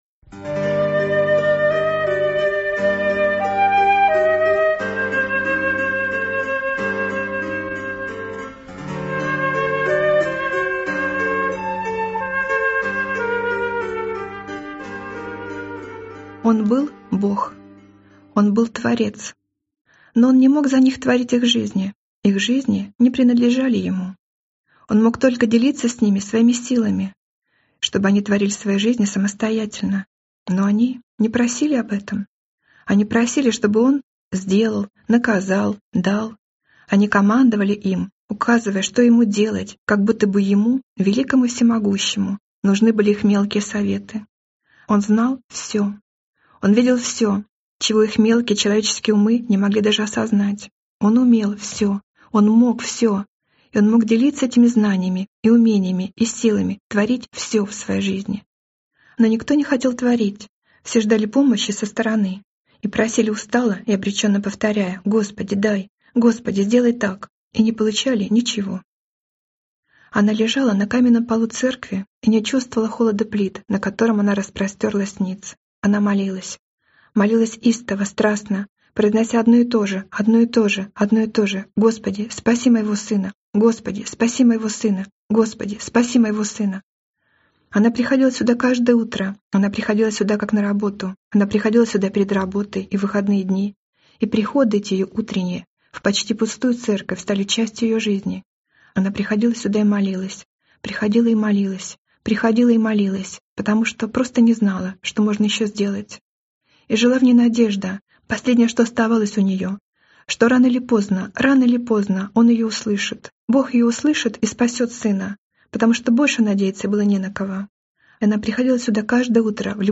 Aудиокнига Сила Бога